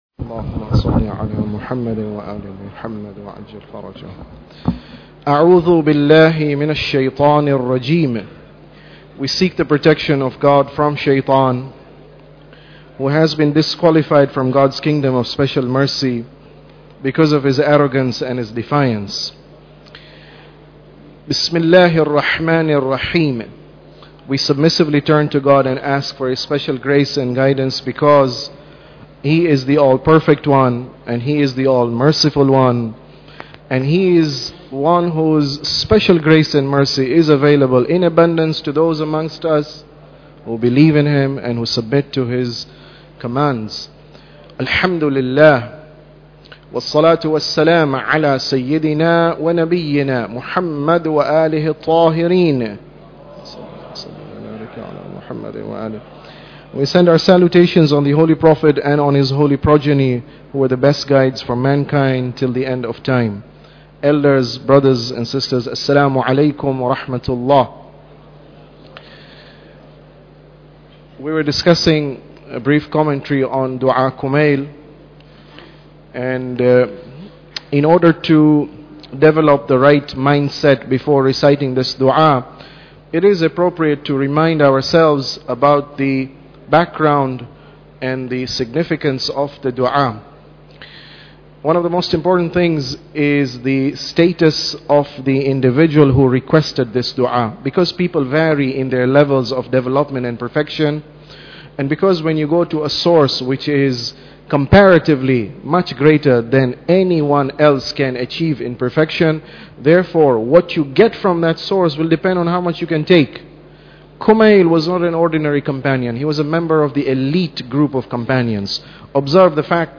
Tafsir Dua Kumail Lecture 13